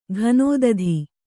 ♪ ghanōdadhi